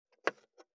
516切る,包丁,厨房,台所,野菜切る,咀嚼音,ナイフ,調理音,まな板の上,料理,
効果音